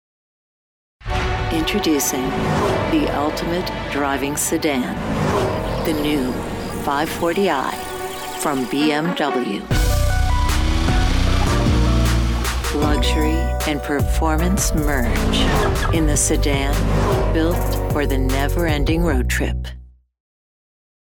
Automotive
With a warm grounded (North American English) voice, wry smile and a storyteller’s heart, I want people to feel something real when they listen.
Acoustic Paneled and Sound Treated- 12'x6' recording studio and workstation